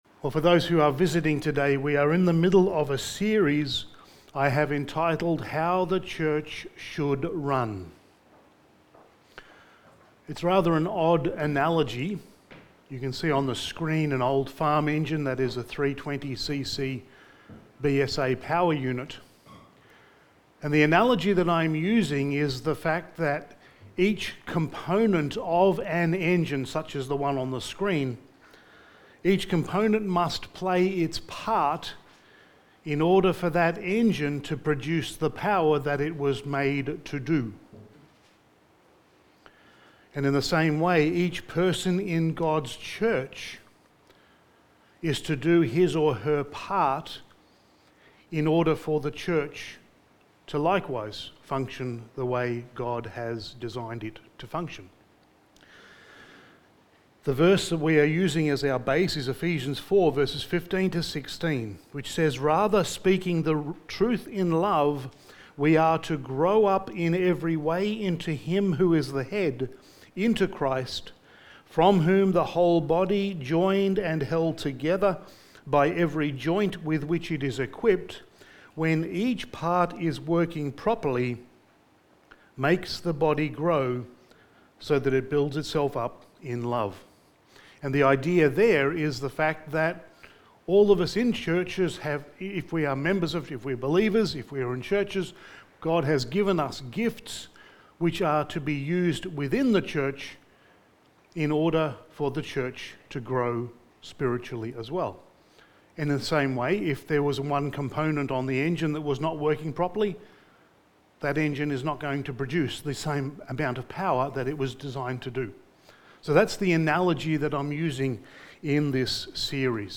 How the Church Should Run Series – Sermon 5: Ordinances in the Local Church
Service Type: Sunday Morning